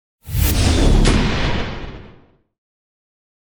magic-missile-001-15ft.ogg